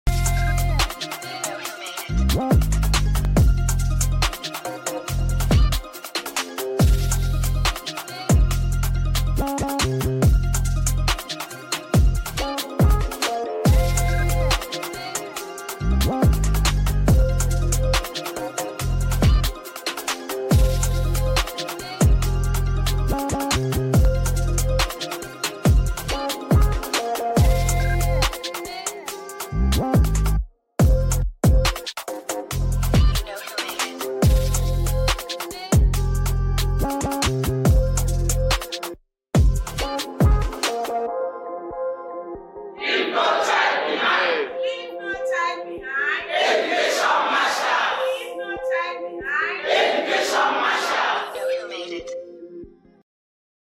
📍 Location: Umuahia, Abia State 🎬 Don’t forget to like, share, and subscribe for more inspiring stories!